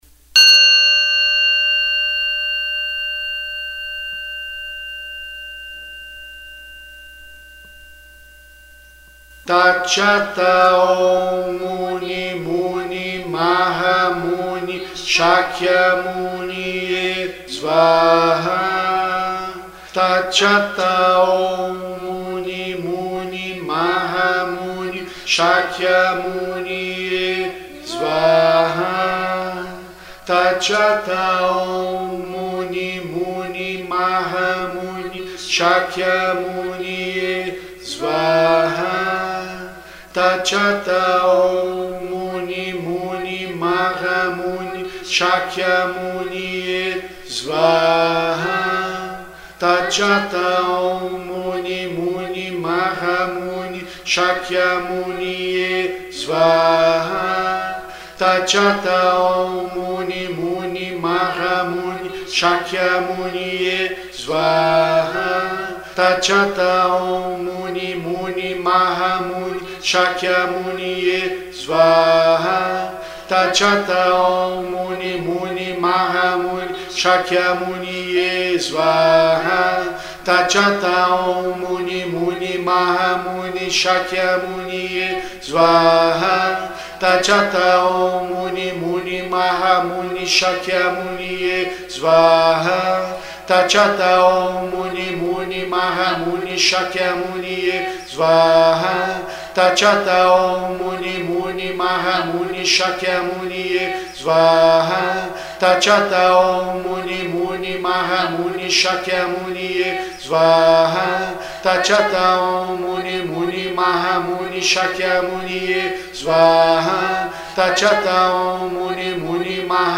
MANTRAS PARA O SENHOR GAUTAMA BUDA
TATIATA OM MUNI MUNI MARRA MUNI CHAQUIAMUNIE SVAARRA